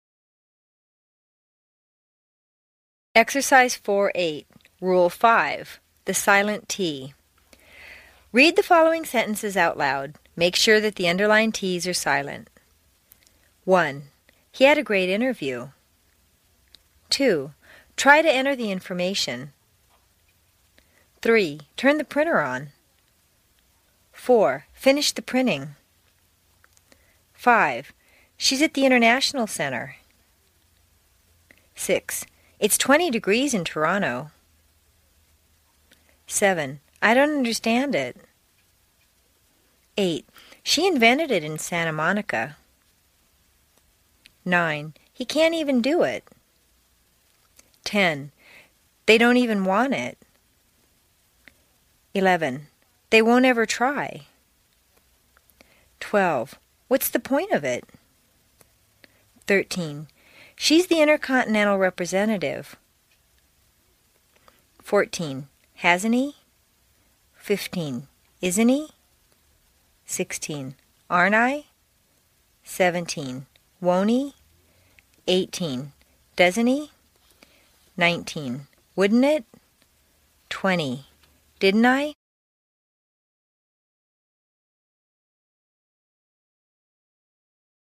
在线英语听力室美式英语正音训练第60期:练习4(8)的听力文件下载,详细解析美式语音语调，讲解美式发音的阶梯性语调训练方法，全方位了解美式发音的技巧与方法，练就一口纯正的美式发音！